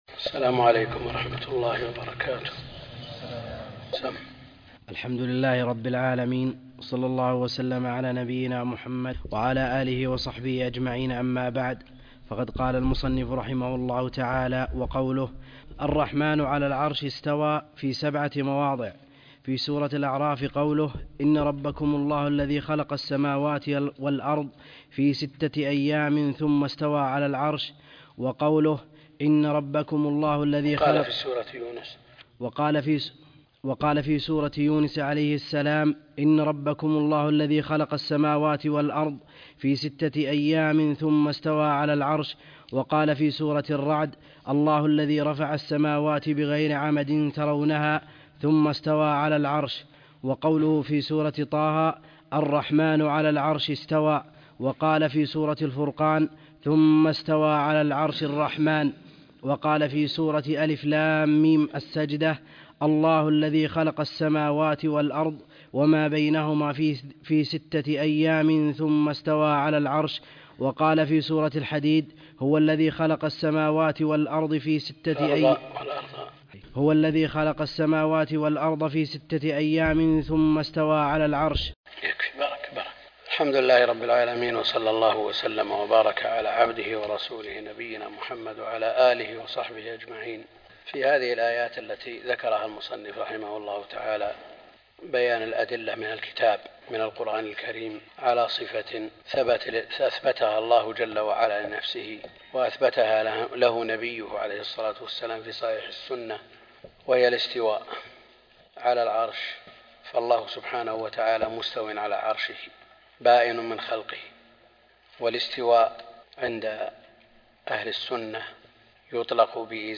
عنوان المادة الدرس (18) شرح العقيدة الواسطية تاريخ التحميل الجمعة 30 ديسمبر 2022 مـ حجم المادة 17.82 ميجا بايت عدد الزيارات 213 زيارة عدد مرات الحفظ 121 مرة إستماع المادة حفظ المادة اضف تعليقك أرسل لصديق